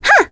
Requina-vox-Jump.wav